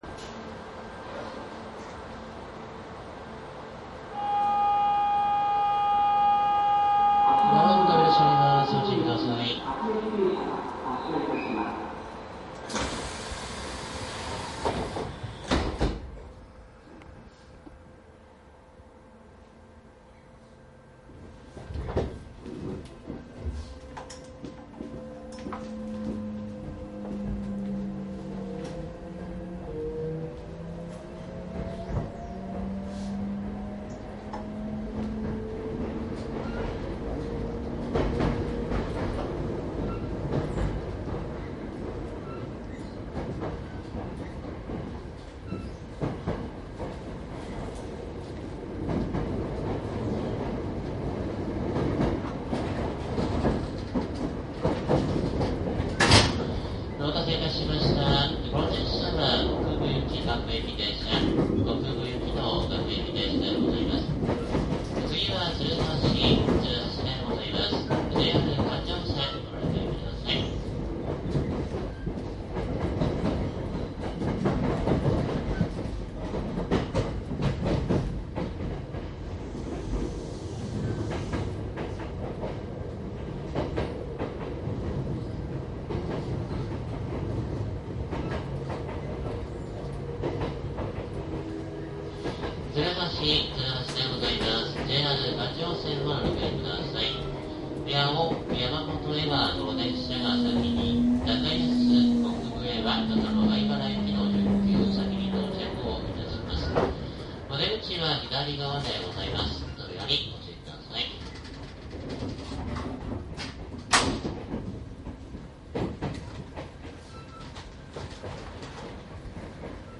♪近鉄大阪線 各停往復 　　走行音　　CD
大阪線で一番多く見かける2両固定三菱VVVF編成などの録音。
いずれもマイクECM959です。TCD100の通常SPモードで録音。
実際に乗客が居る車内で録音しています。貸切ではありませんので乗客の会話やが全くないわけではありません。